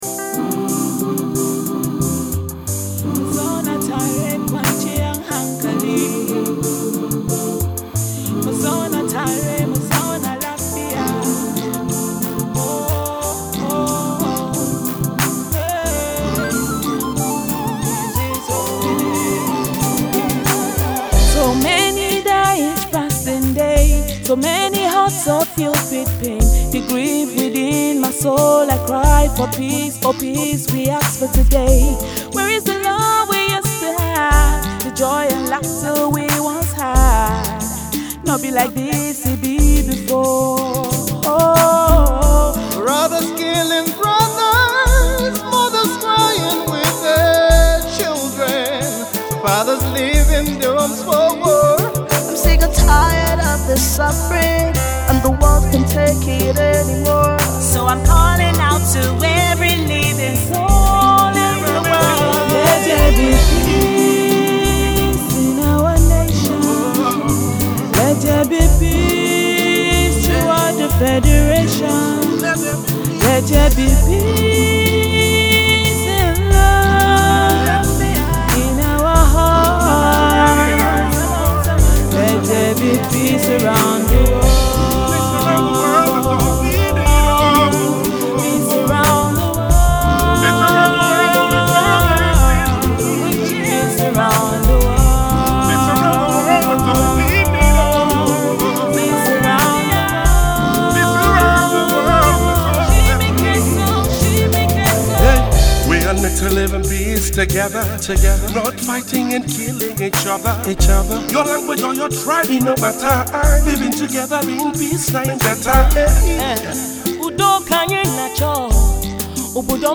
an army of inspiring singers team up to preach
Gospel